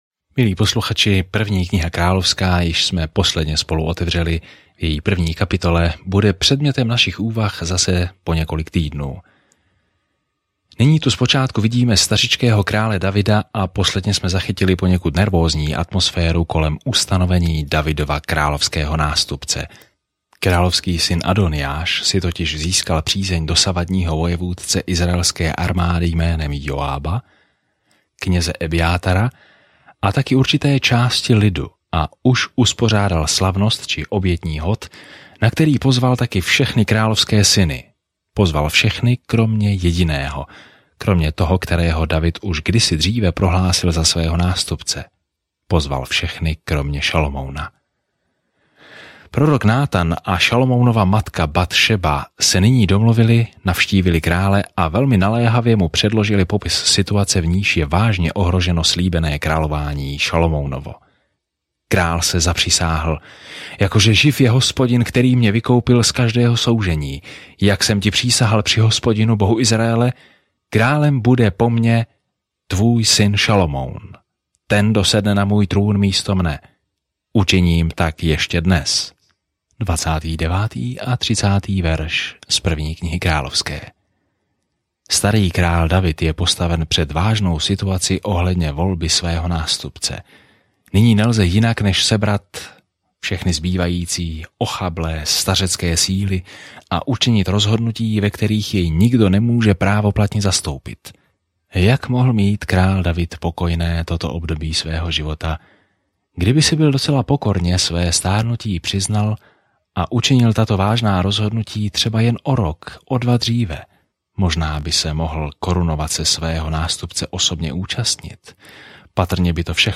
Písmo 1 Královská 1:31-53 Den 1 Začít tento plán Den 3 O tomto plánu Kniha králů pokračuje v příběhu o tom, jak izraelské království za Davida a Šalomouna vzkvétalo, ale nakonec se rozpadlo. Denně cestujte po 1. králi, poslouchejte audiostudii a čtěte vybrané verše z Božího slova.